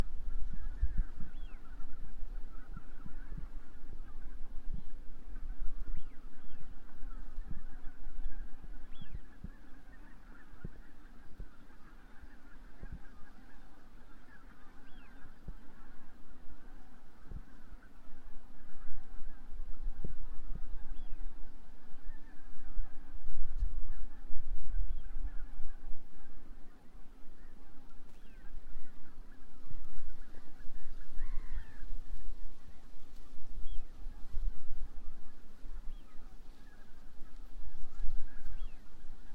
Birds -> Ducks ->
Eurasian Wigeon, Mareca penelope
StatusVoice, calls heard